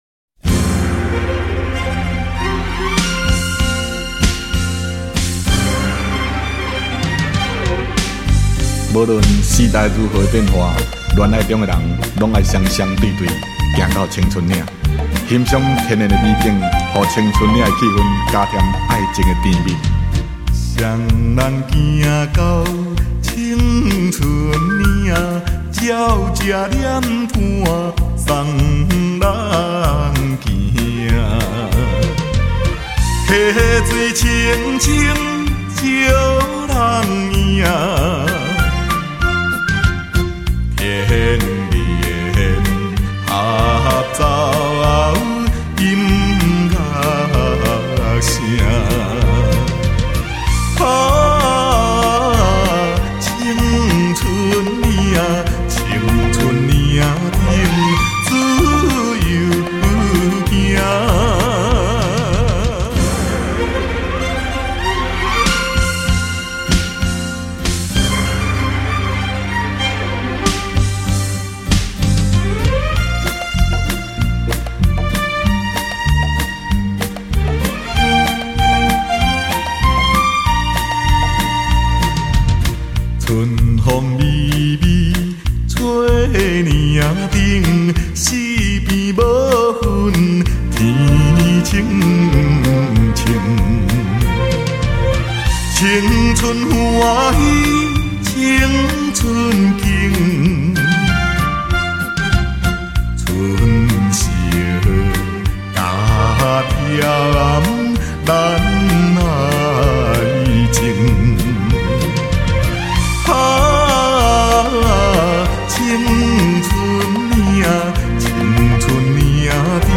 优美的弦乐伴奏
脚踩大鼓与贝司一起下拍子时的噗噗声效果很好，它是既软又有弹性的，假若您听不到这种噗噗声，那就代表您的低频有问题。